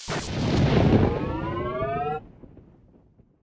gauss_wave.ogg